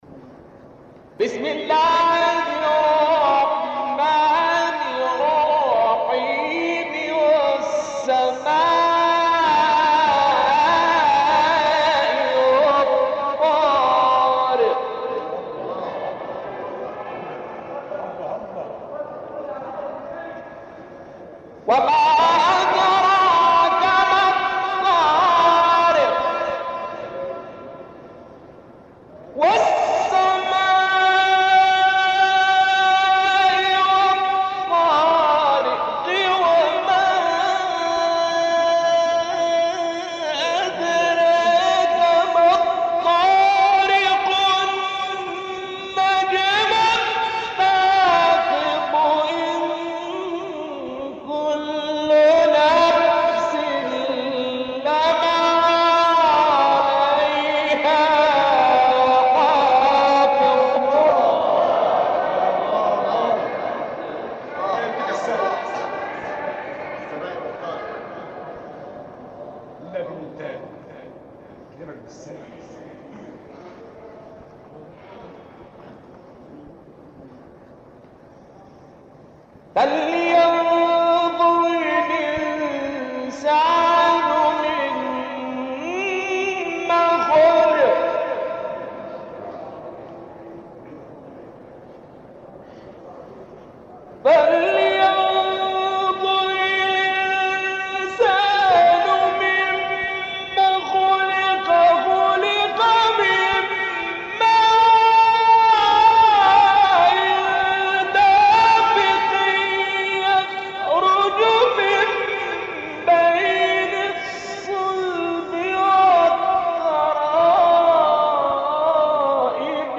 الشيخ شعبان الصياد رحمه الله-قصار السور من تسجيلات قرآن الفجر مسجد الإمام الحسين